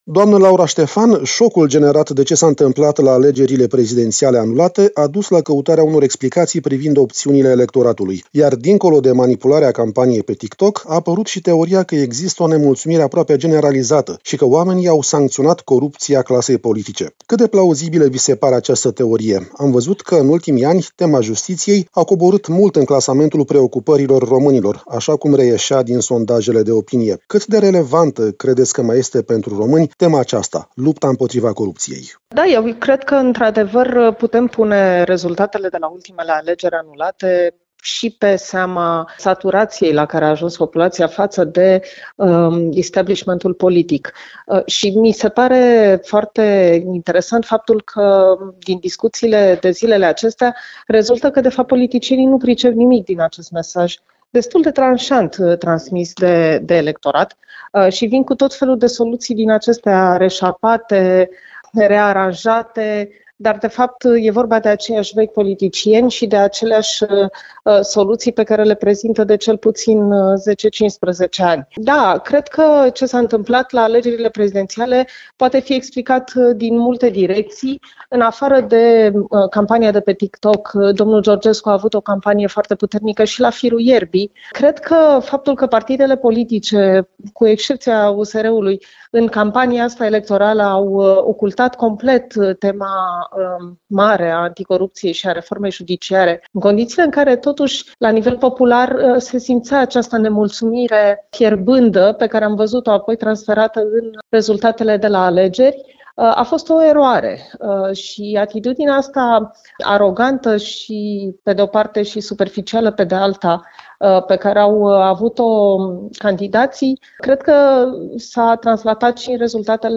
AUDIO | Foarte mulți români sunt revoltați de atitudinea clasei politice, pe care o acuză de corupție. Interviu